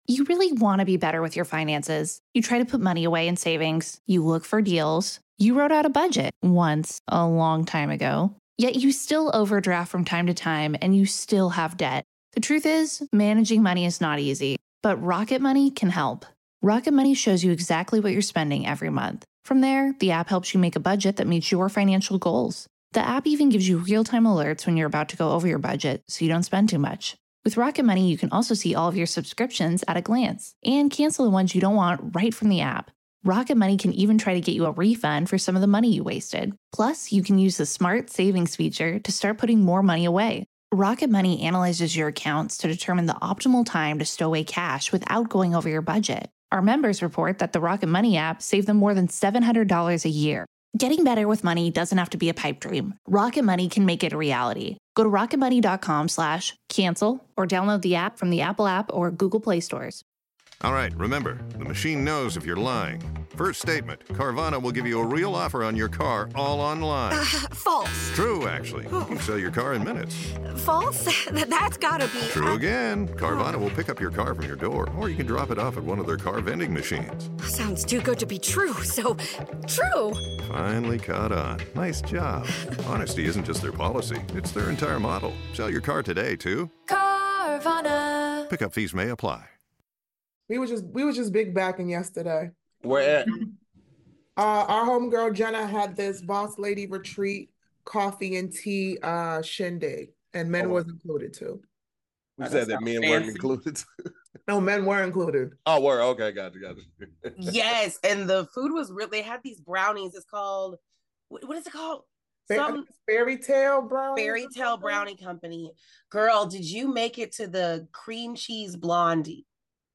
Each week, the SquADD will debate topics and vote at the end to see what wins.